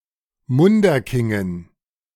Munderkingen (German pronunciation: [ˈmʊndɐˌkɪŋən]
De-Munderkingen.ogg.mp3